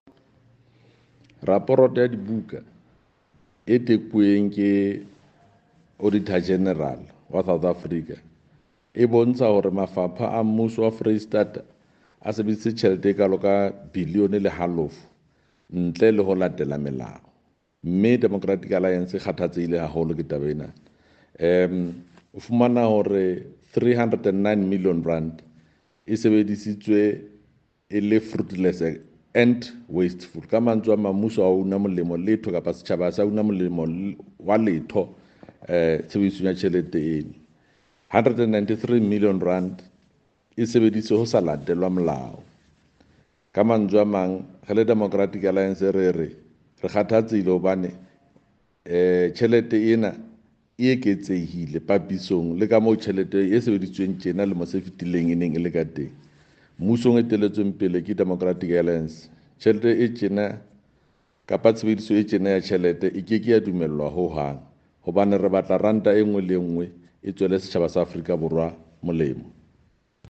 Sesotho soundbite by Jafta Mokoena MPL with image here